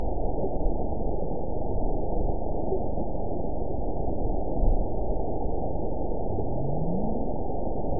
event 912484 date 03/27/22 time 14:25:33 GMT (3 years, 1 month ago) score 9.63 location TSS-AB05 detected by nrw target species NRW annotations +NRW Spectrogram: Frequency (kHz) vs. Time (s) audio not available .wav